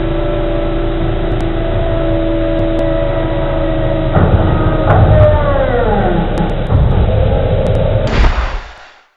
gearOLD.wav